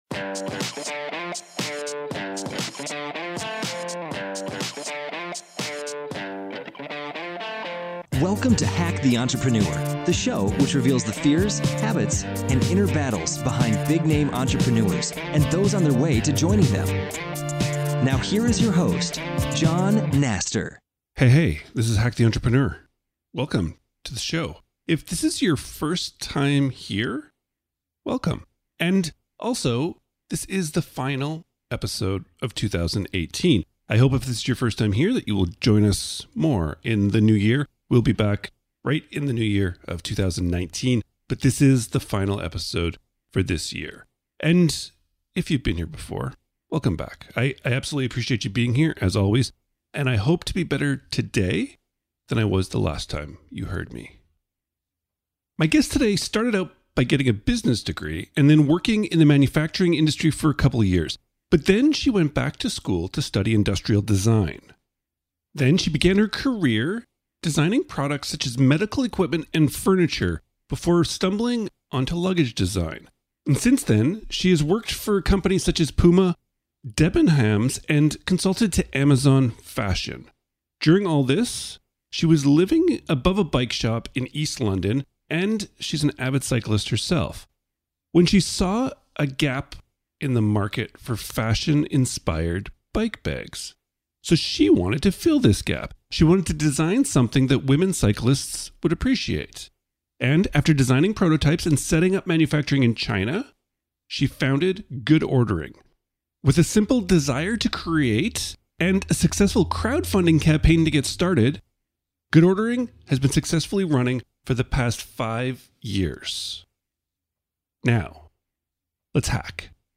In this conversation, we discuss Using your bosses as early business and growth mentors How vulnerability can drive the foundations of your business Finding and harnessing the entrepreneurial mindset in the most unlikely places Now, let's hack...